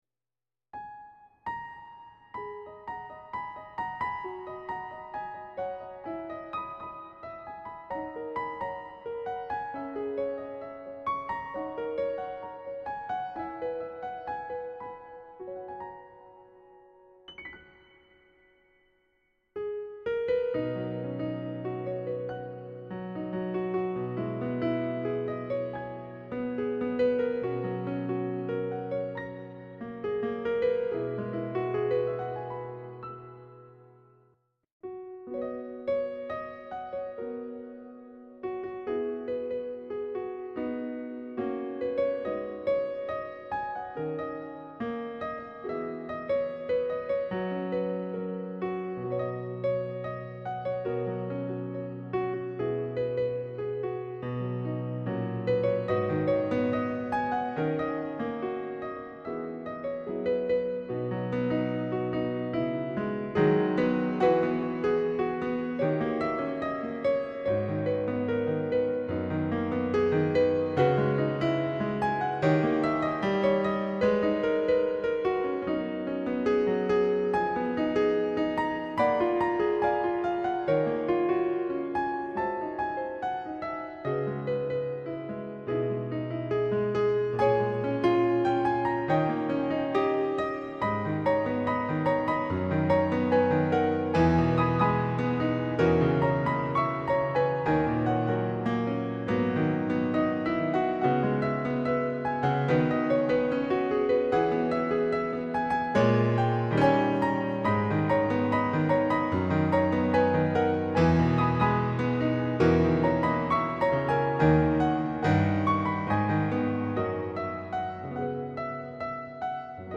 J-pop 弾いてみた編